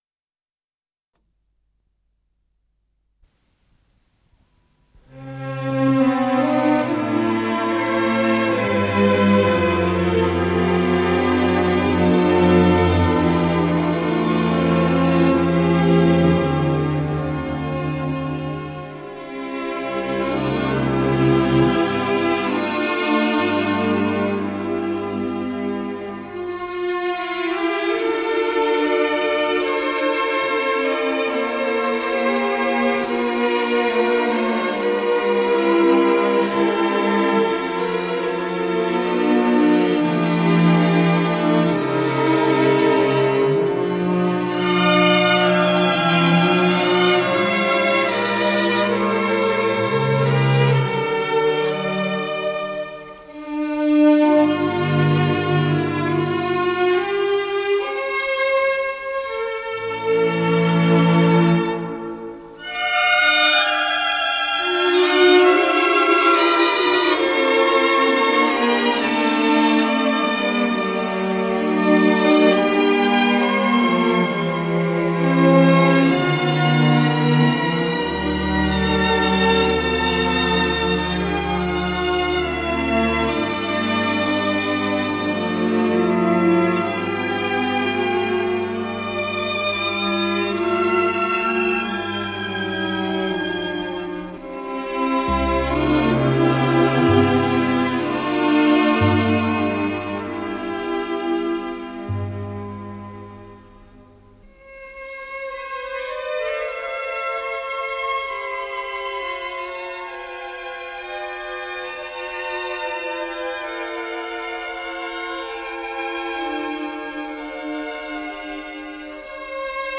ПодзаголовокДо мажор
ВариантДубль моно